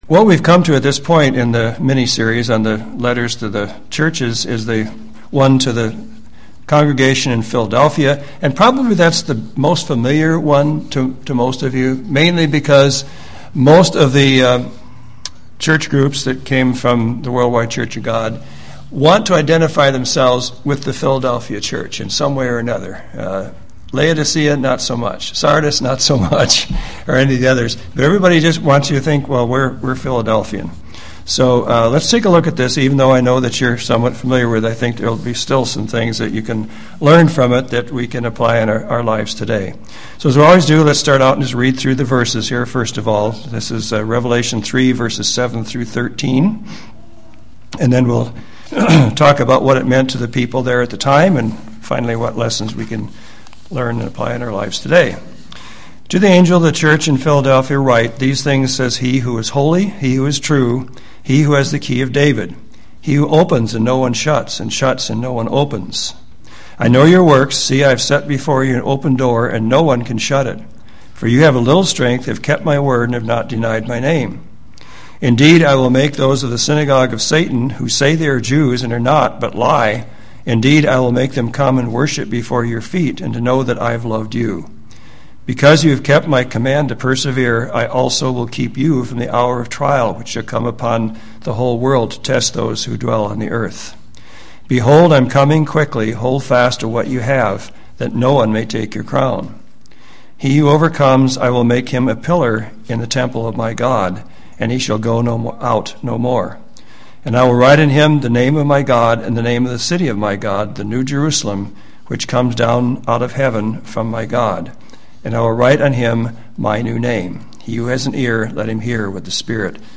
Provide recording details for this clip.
Lessons from the Letters – Philadelphia Bend 11-5-11 Medford 12-10-11